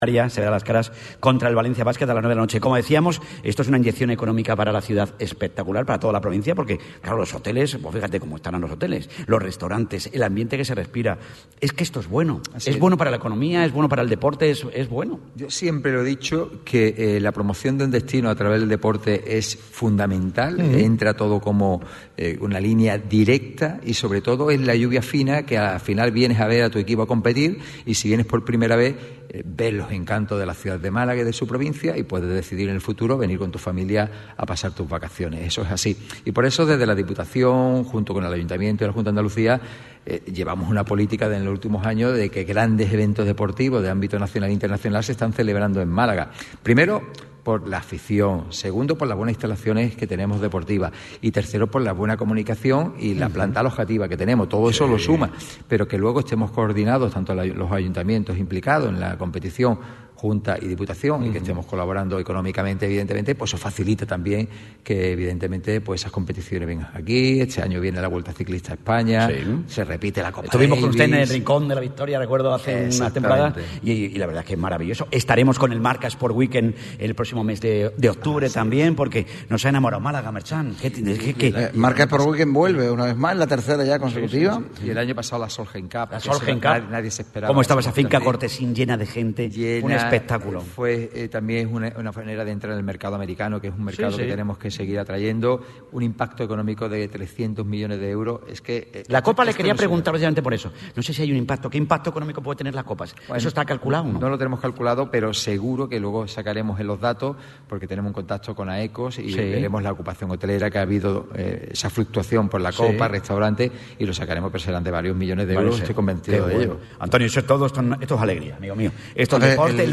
El presidente de la Diputación de Málaga ha acudido al Auditorio Edgar Neville acompañando al equipo de Radio MARCA que hoy, de manera especial y con el motivo de la celebración de la Copa del Rey de baloncesto, se ha desplazado hasta la capital de la Costa del Sol.